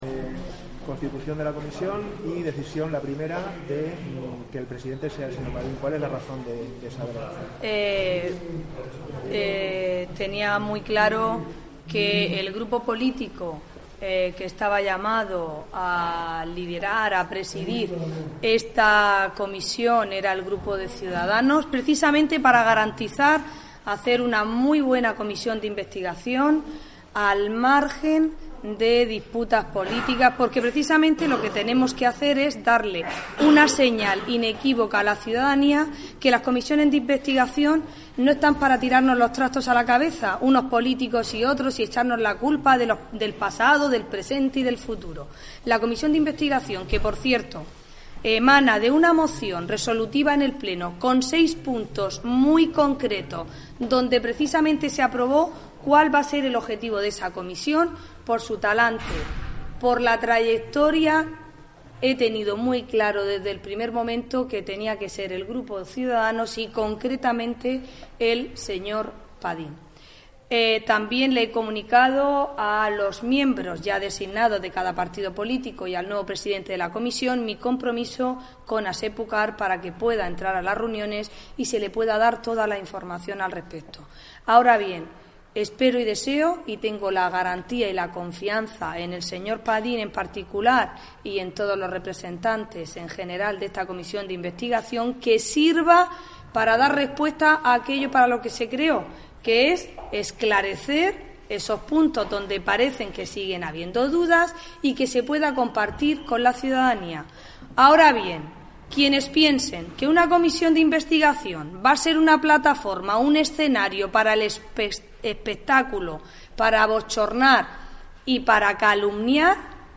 La reunión ha tenido lugar esta mañana en la Sala Multiusos del Edificio Administrativo